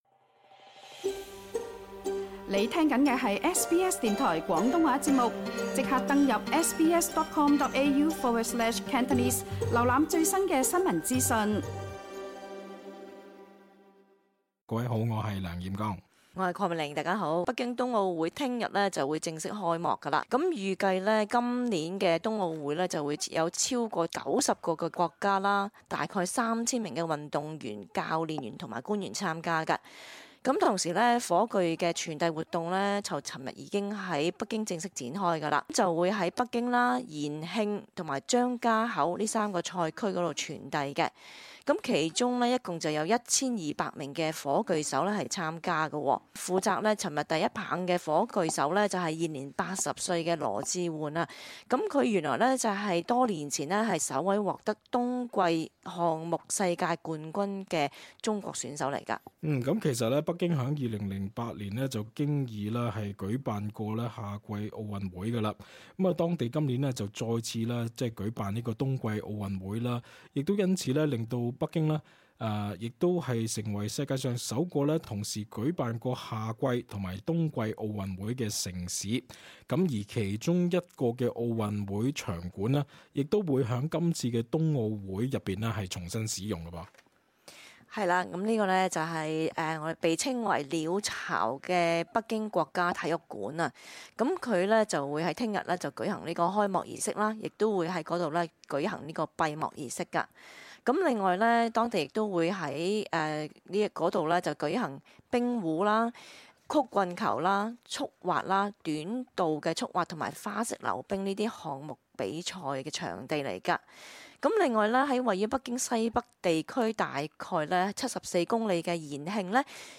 cantonese_-_talkback_-_winter_olympics_-_feb_3.mp3